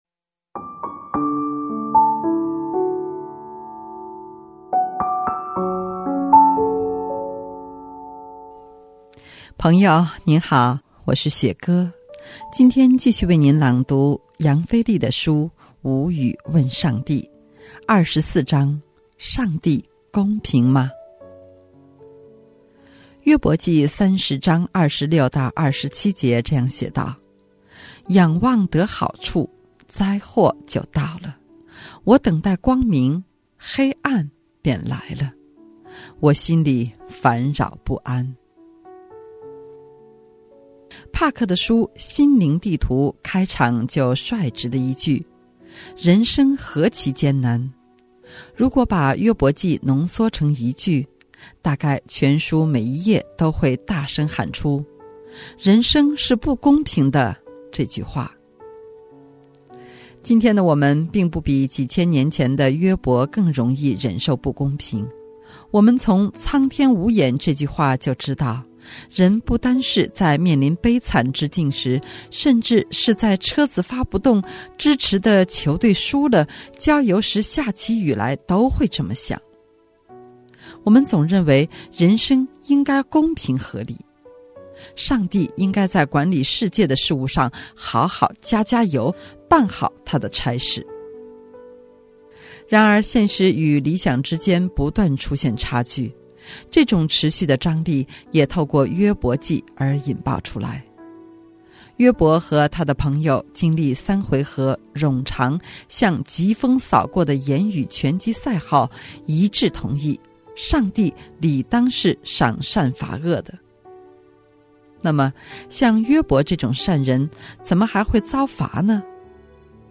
今天继续为你朗读杨腓力的书《无语问上帝》。